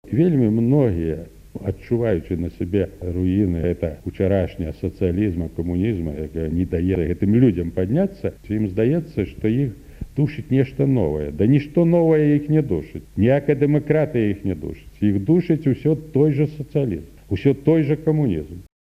Інтэрвію з Алесем Адамовічам (фрагмэнт)